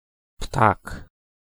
Ääntäminen
France (Paris): IPA: /wa.zo/